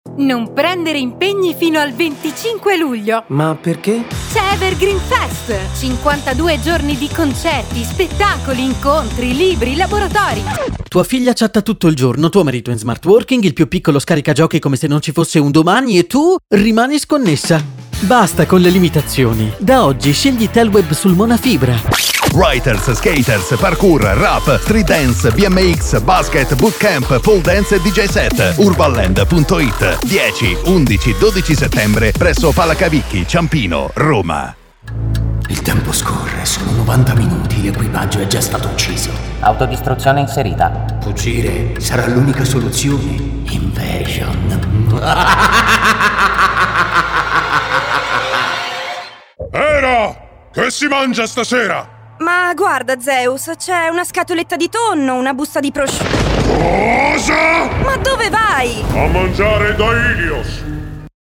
Realizzazione Spot Pubblicitari
Utilizziamo voci che provengono dal mondo del doppiaggio e del teatro, creando una fucina di talenti anche per i nostri clienti.